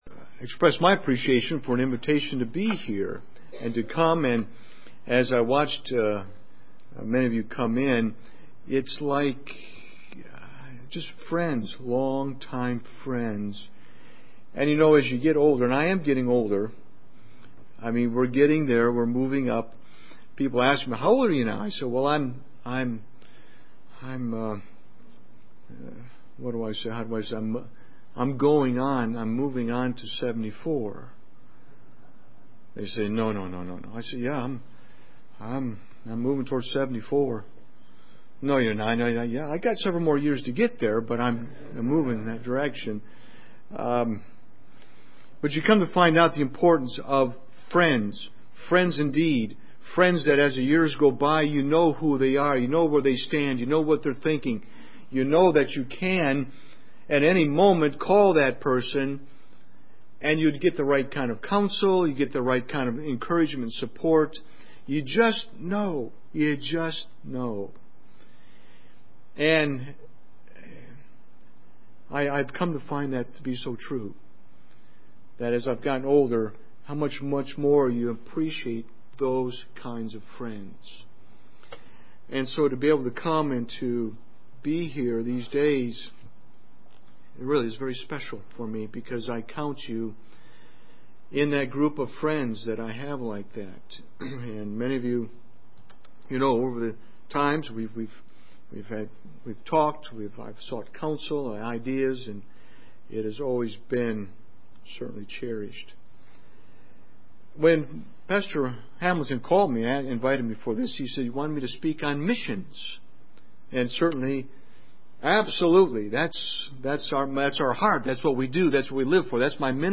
2014 OBF Conference Christ Evangelism Missions